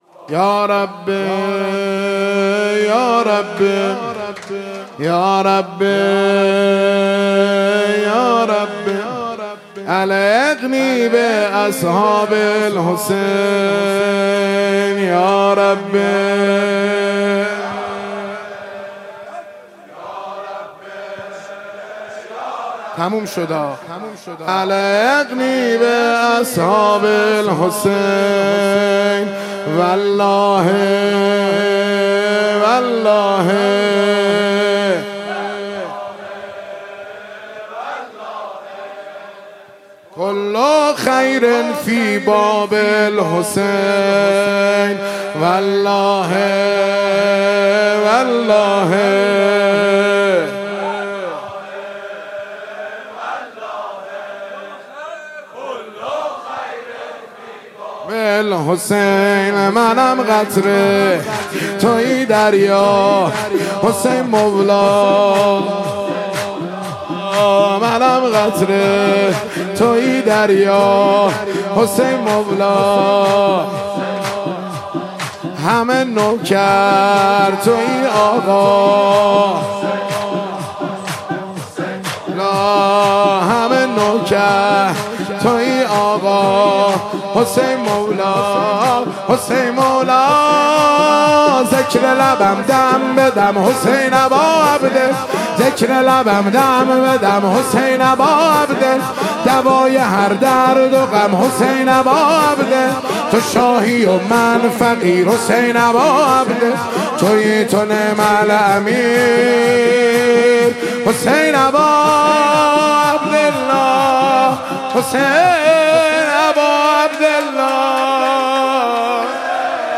مراسم عزاداری شب تاسوعا محرم الحرام - موکب‌الحسین
رجز خوانی -مراسم_عزاداری_شب_تاسوعا_محرم_الحرام (2).mp3